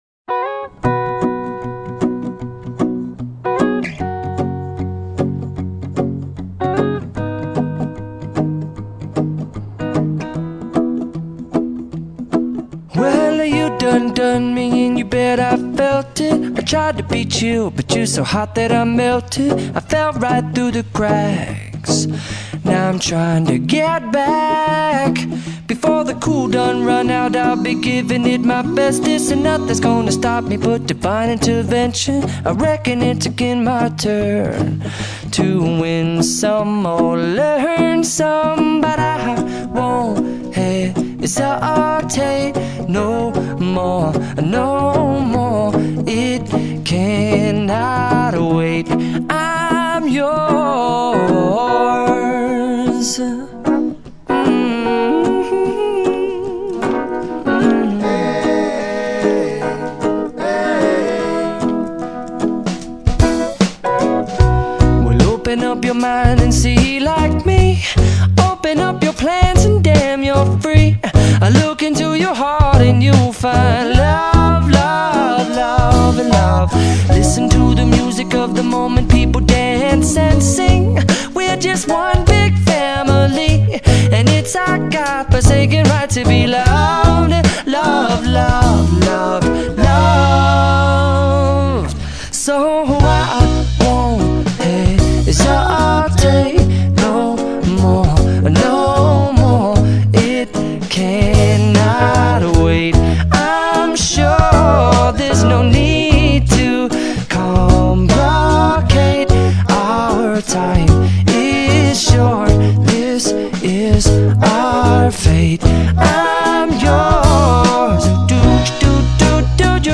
Category: Acoustic Music